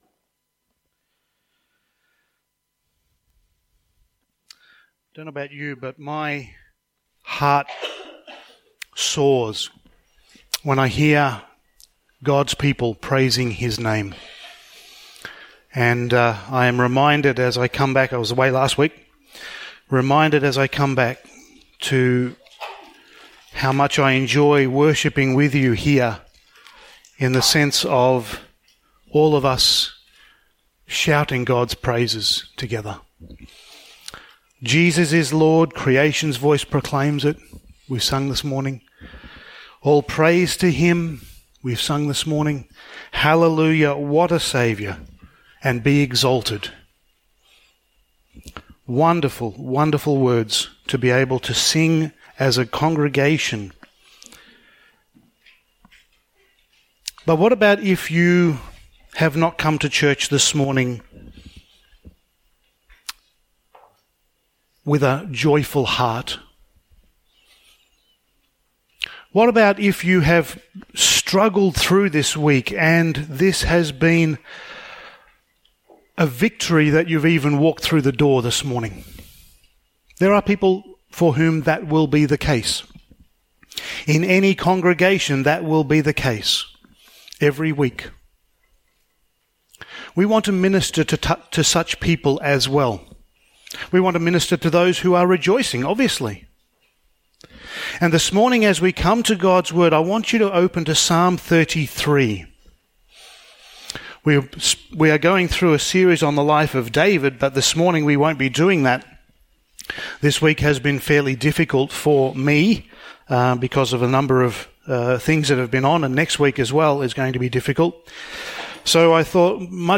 Topical Sermon
Service Type: Sunday Morning